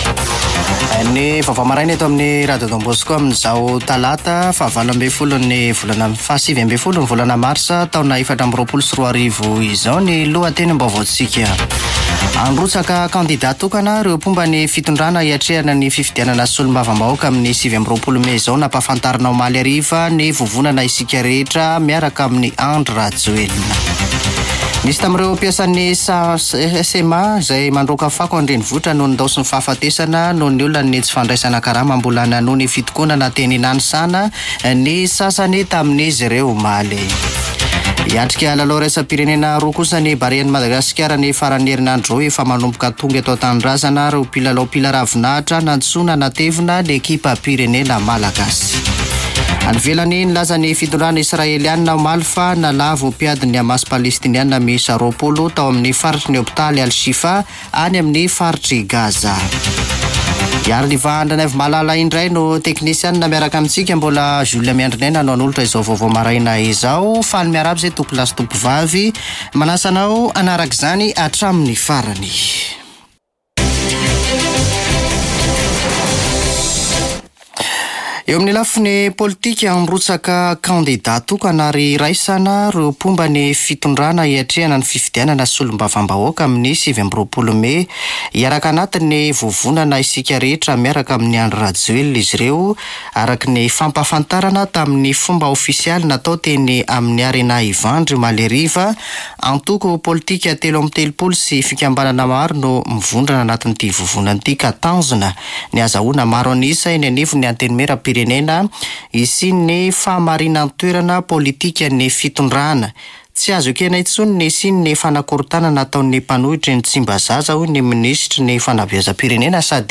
[Vaovao maraina] Talata 19 marsa 2024